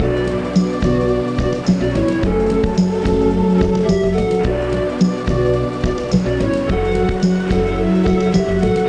00030_Sound_technogroove22-8